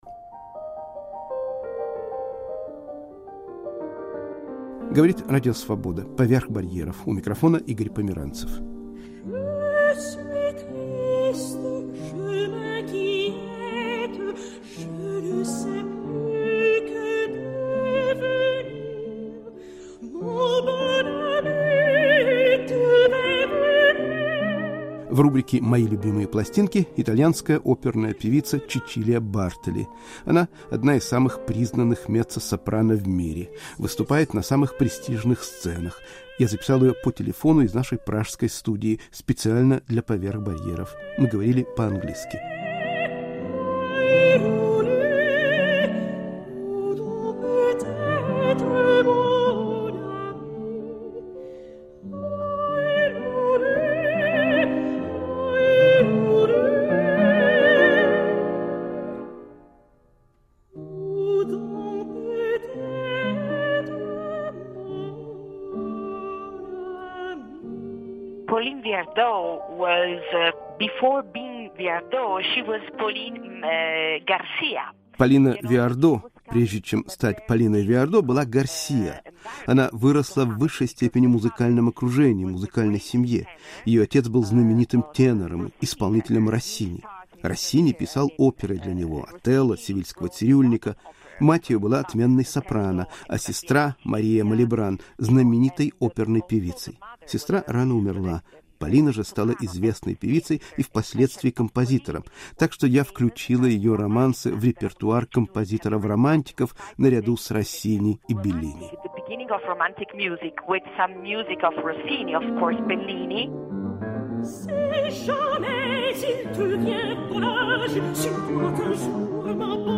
Итальянская певица Чечилия Бартоли в "Поверх барьеров"